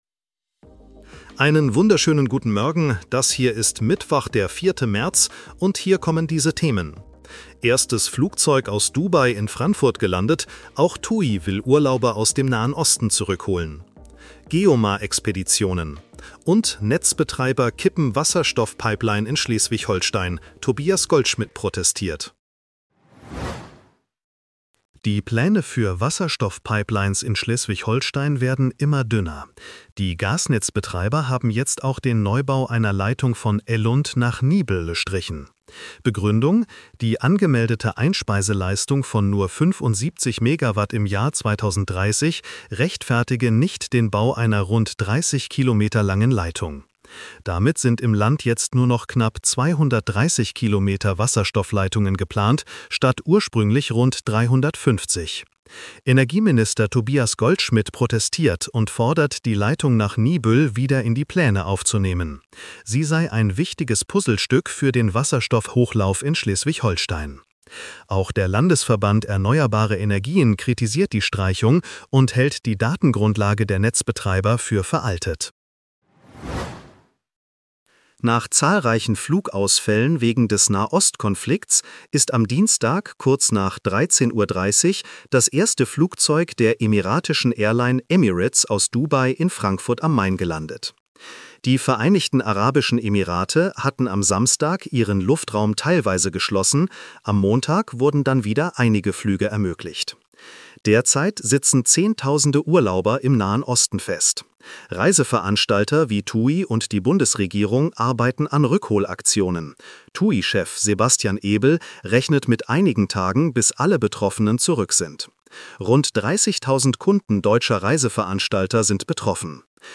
Nachrichten-Botcast bekommst Du ab 7:30 Uhr die wichtigsten Infos